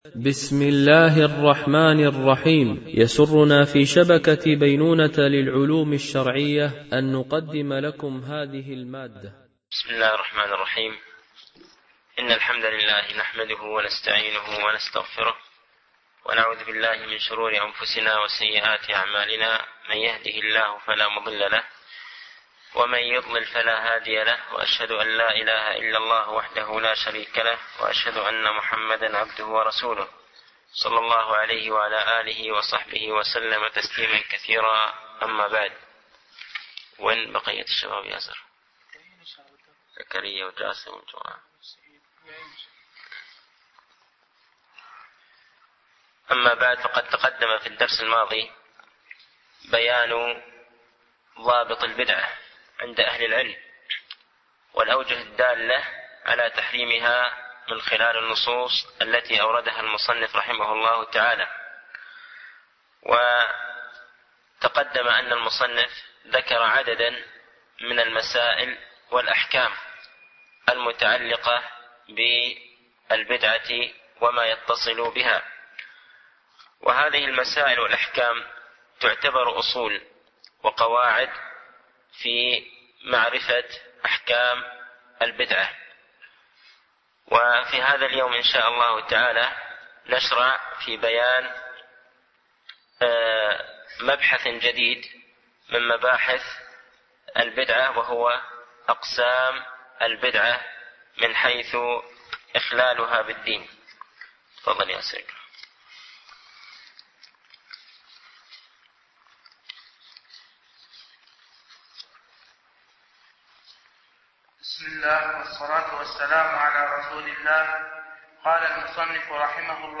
شرح أعلام السنة المنشورة ـ الدرس 129 ( إلى كم قسم تنقسم البدعة باعتبار إخلالها بالدين ؟ ما هي البدع المكفرة ؟ )